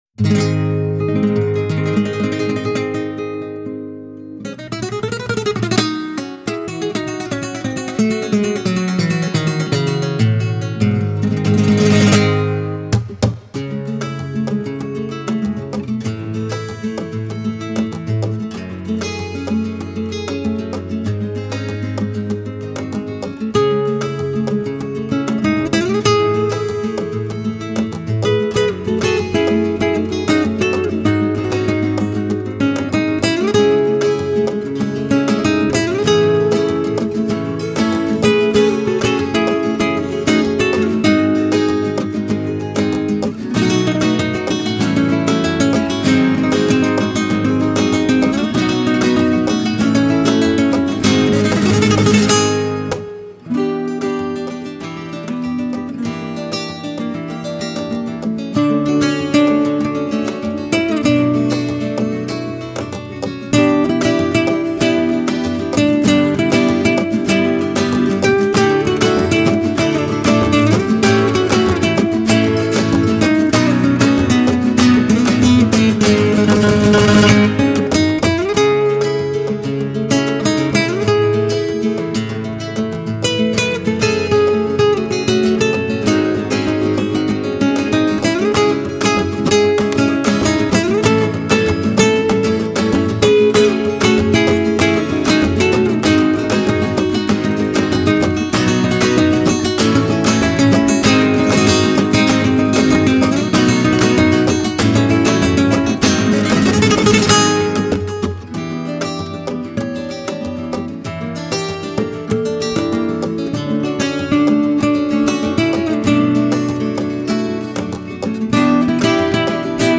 Нью-эйдж